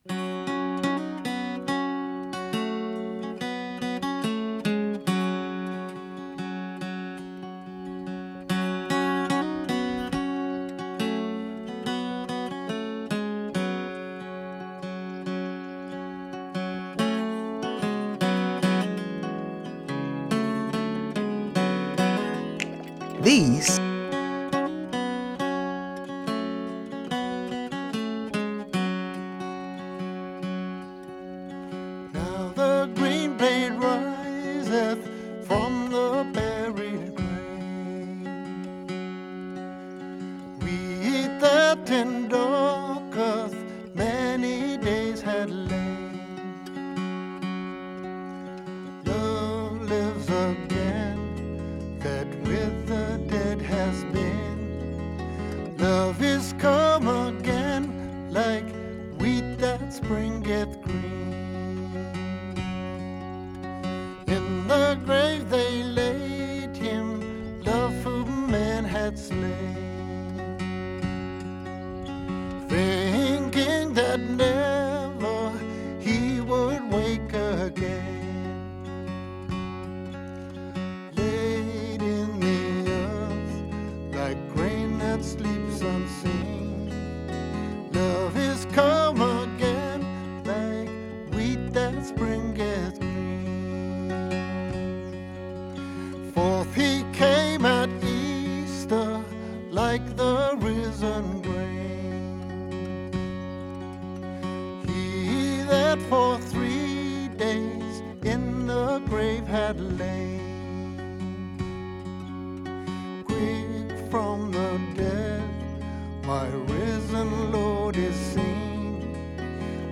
Recorded on the first day of Spring March 20th 2025, members of the Camphill Hudson Radio Group discuss the things they love most about springtime in upstate New York as well as what they are giving up (or taking up) for Lent.